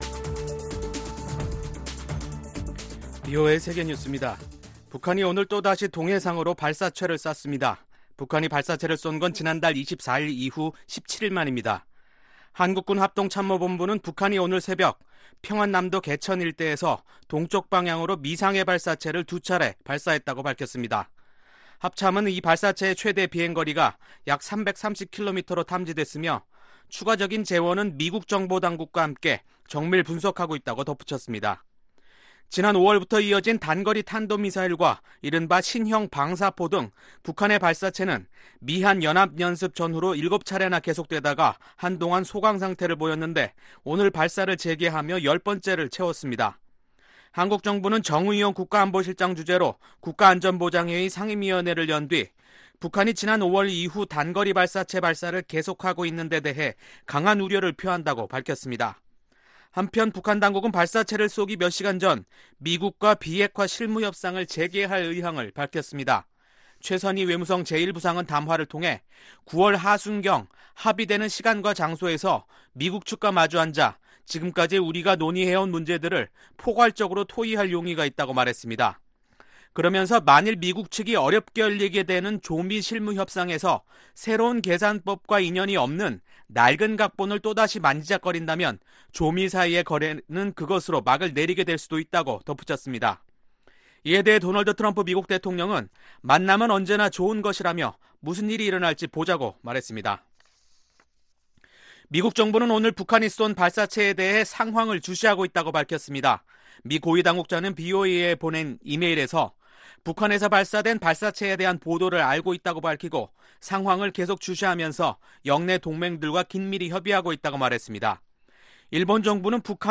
VOA 한국어 간판 뉴스 프로그램 '뉴스 투데이', 2019년 9월 5일3부 방송입니다. 북한이 미국에 실무협상 제의를 한지 몇시간 만인 10일 오전 평안남도 개천에서 동쪽 방향으로 단거리 발사체 두발을 발사했습니다.